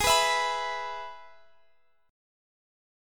Listen to G#m#5 strummed